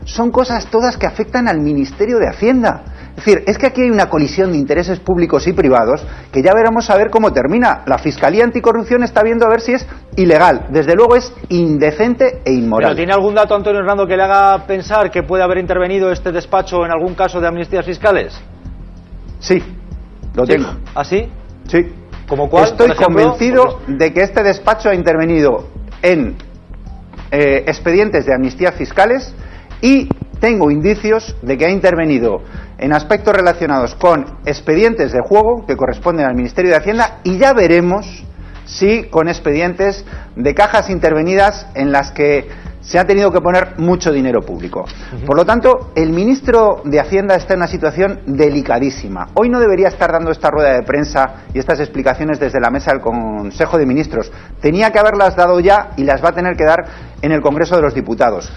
Fragmento de la entrevista de Antonio Hernando en Las mañanas de Cuatro TV. 20/3/2015